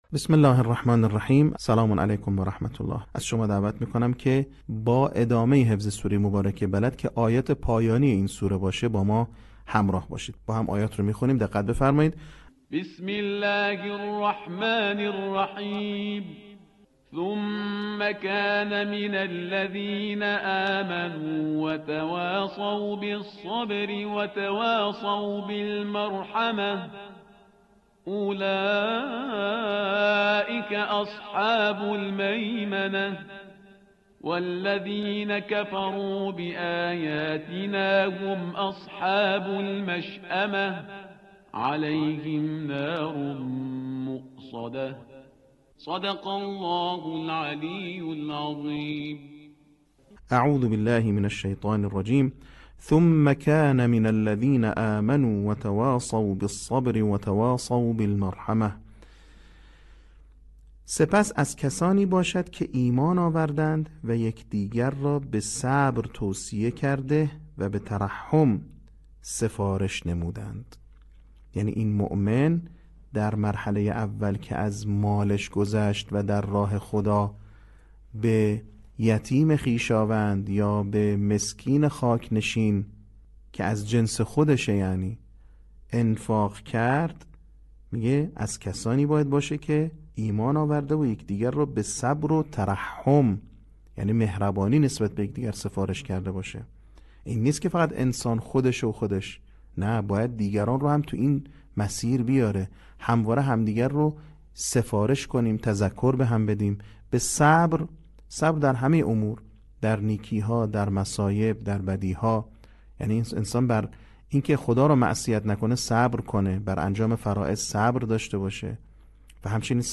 صوت | بخش چهارم آموزش حفظ سوره بلد